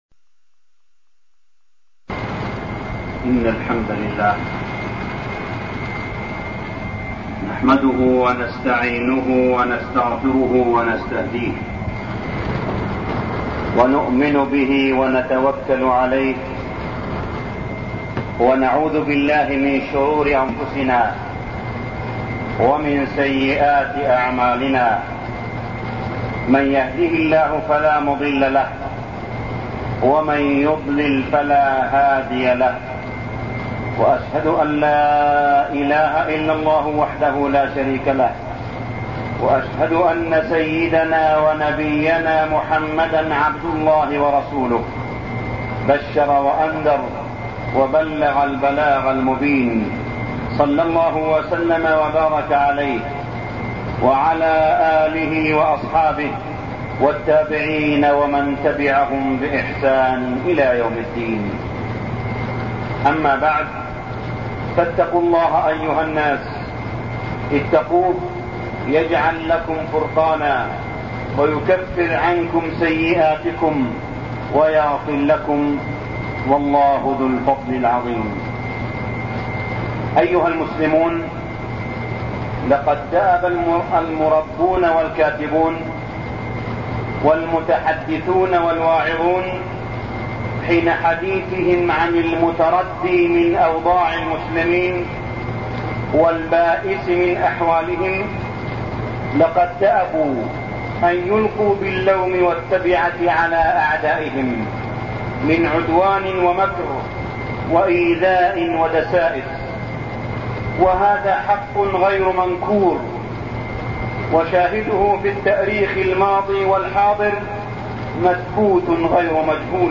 خطاب إلى الشعب الأفغاني
تاريخ النشر ١٠ شعبان ١٤١٤ هـ المكان: المسجد الحرام الشيخ: معالي الشيخ أ.د. صالح بن عبدالله بن حميد معالي الشيخ أ.د. صالح بن عبدالله بن حميد خطاب إلى الشعب الأفغاني